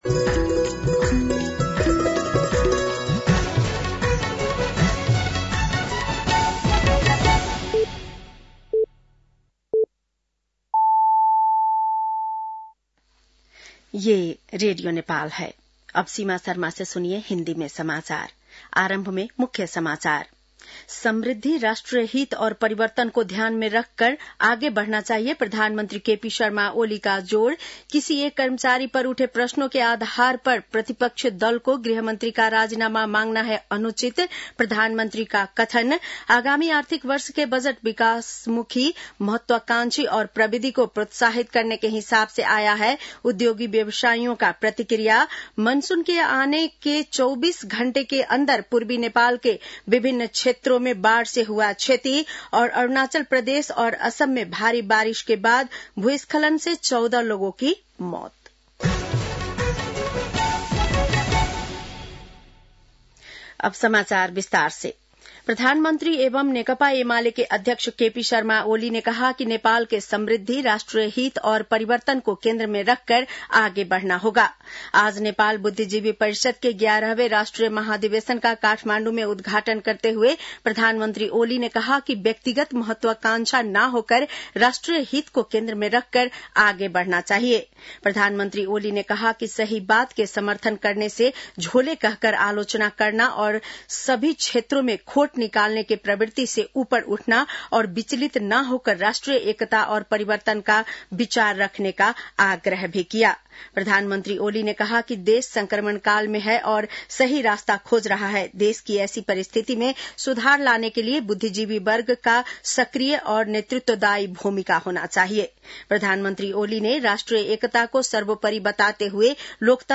बेलुकी १० बजेको हिन्दी समाचार : १७ जेठ , २०८२
10-PM-Hindi-NEWS-.mp3